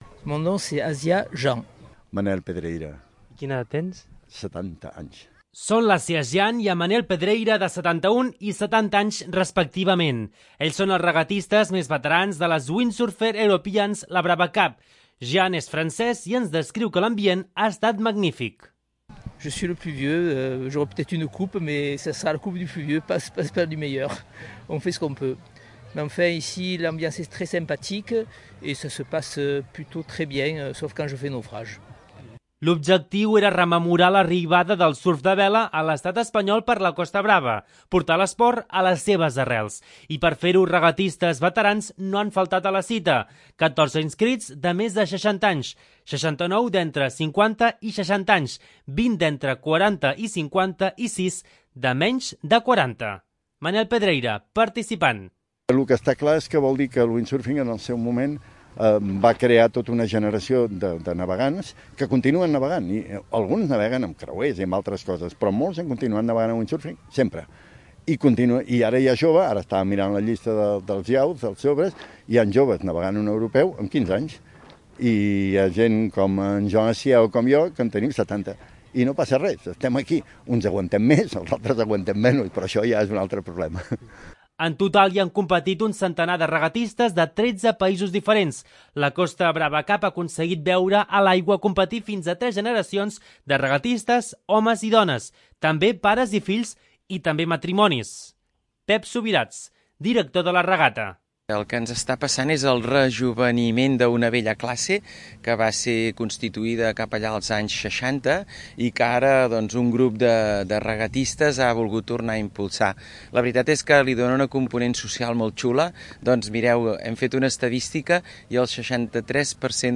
2. L'Informatiu